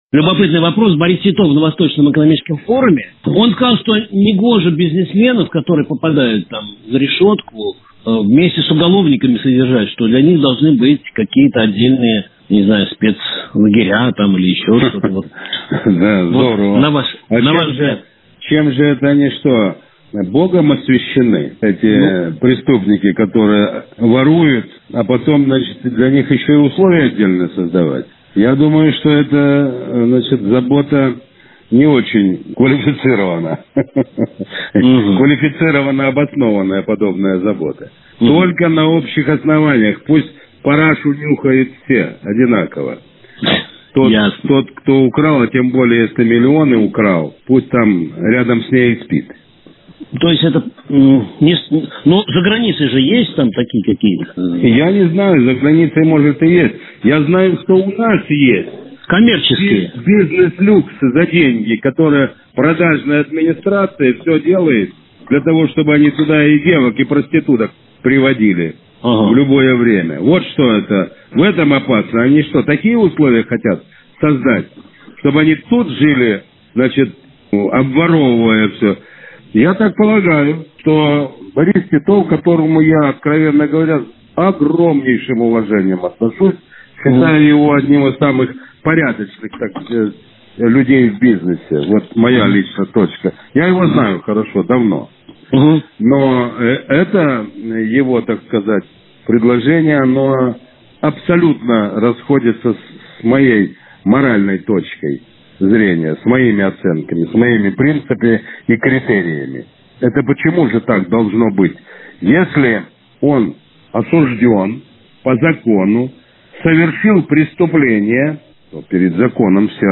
Экс-глава МВД и бывший вице-премьер РФ в эфире Радио «Комсомольская правда» прокомментировал предложение об отдельных тюрьмах для осужденных за экономические преступления [аудио].
Генерал армии Анатолий Куликов — в эфире Радио «КП»: «Товарищ Жеглов прав — вор должен сидеть в тюрьме!»